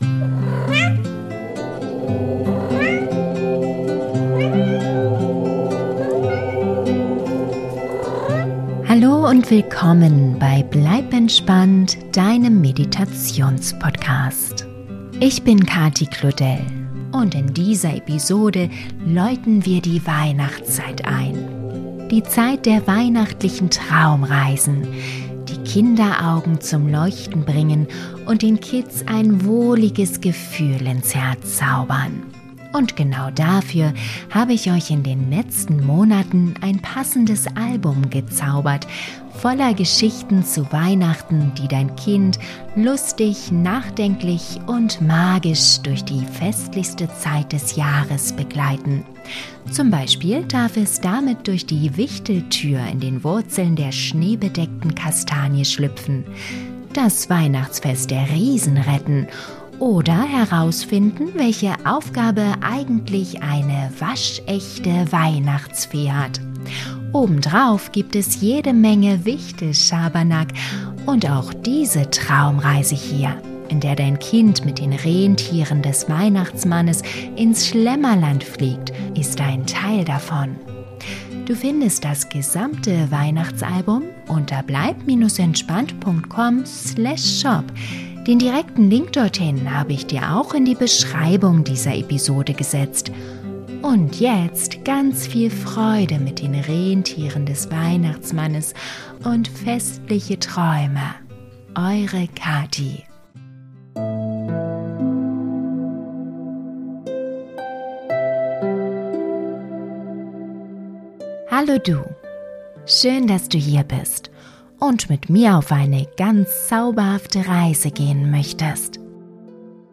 Traumreise für Kinder zum Einschlafen - Mit Santas Rentieren ins Schlemmerland - Geschichte zu Weihnachten ~ Bleib entspannt!